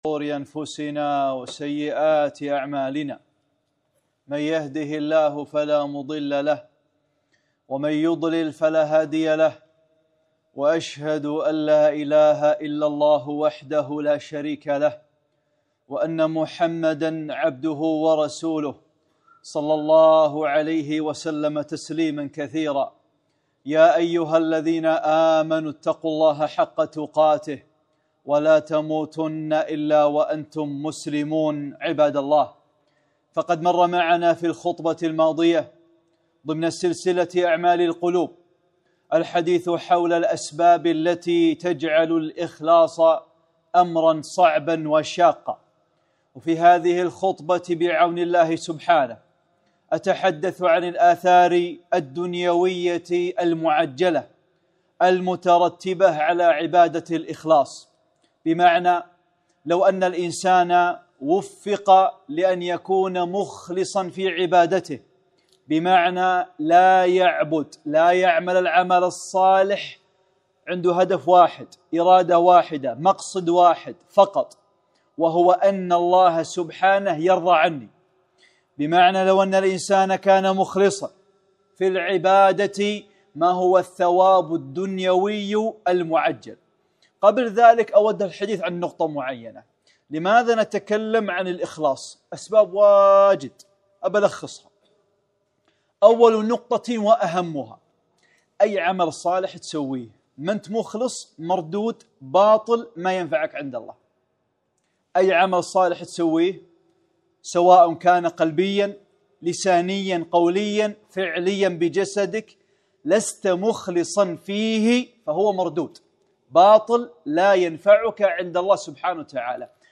(13) خطبة - الآثار الدنيوية المعجلة للإخلاص | سلسلة أعمال القلوب